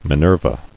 [Mi·ner·va]